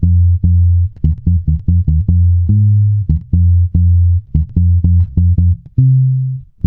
-MM RAGGA F.wav